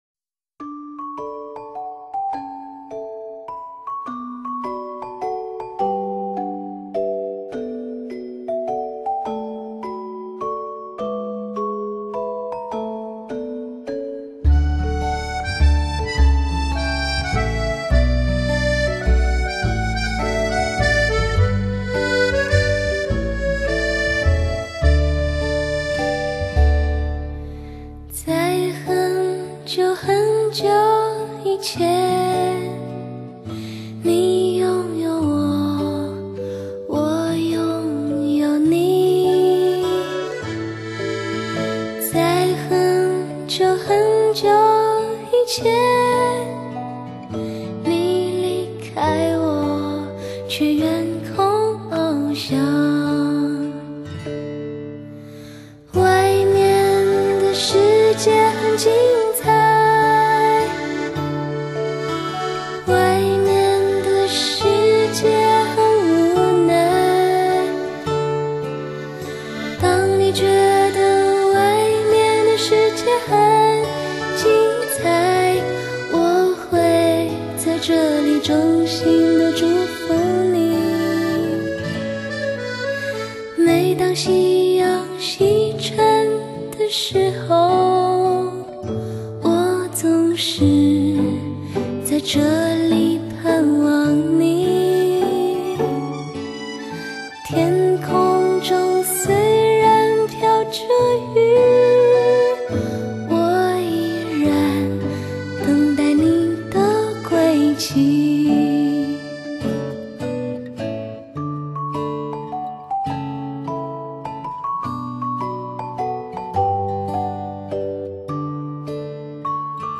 12首好听对唱情歌
以男女对唱的方式